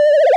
tone.wav